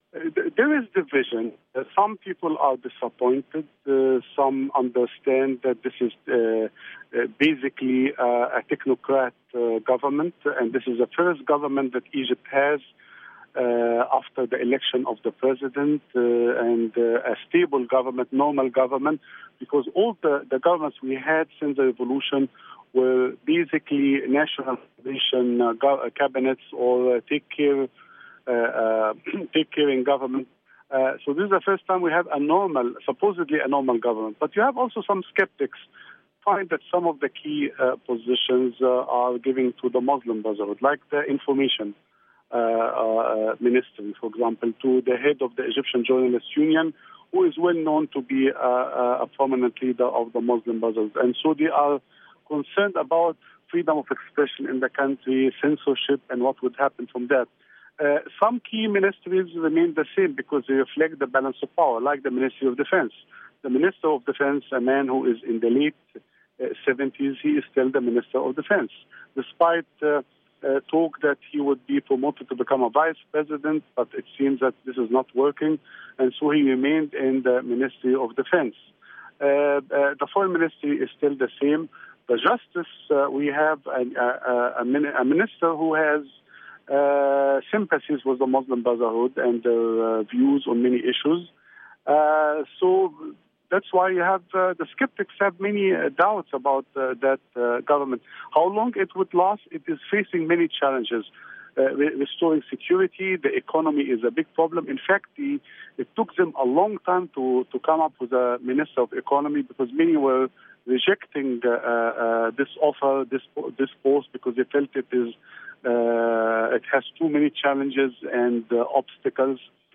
inter